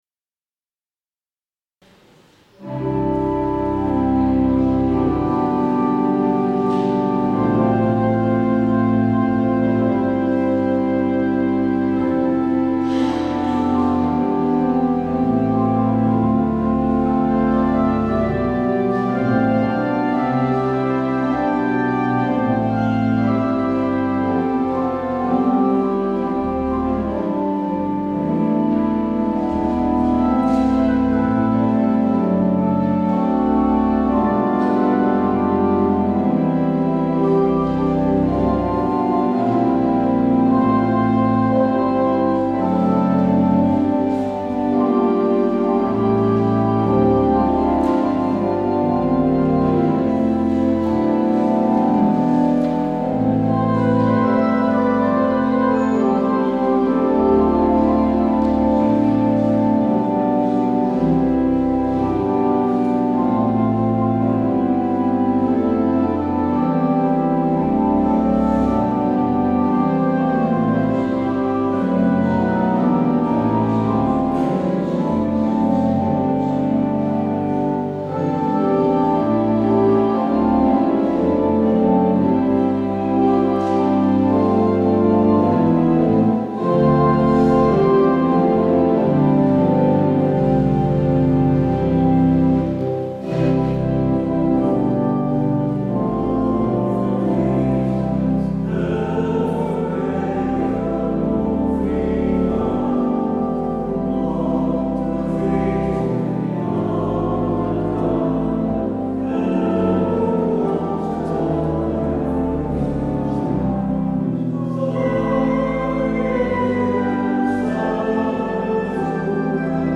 Luister deze kerkdienst hier terug: